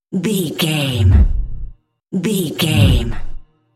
Deep whoosh fast pass by
Sound Effects
dark
futuristic
intense
whoosh